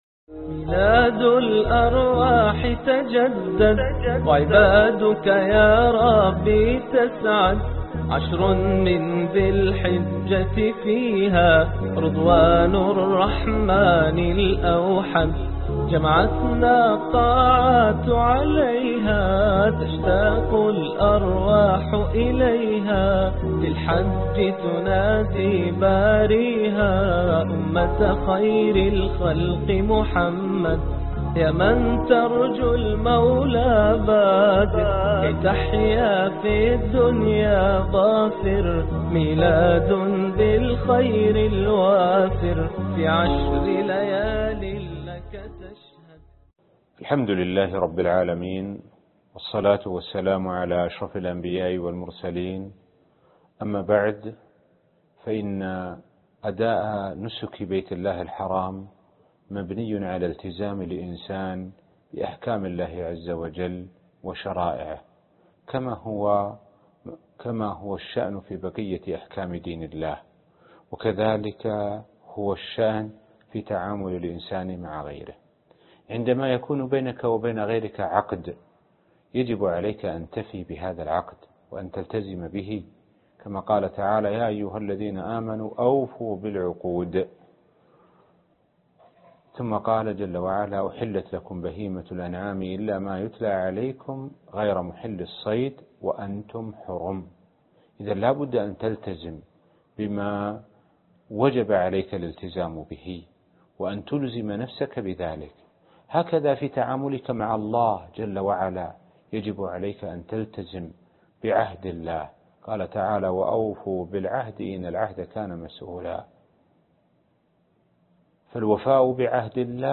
الدرس الخامس ( ميلاد جديد - الحج ) - الشيخ سعد بن ناصر الشثري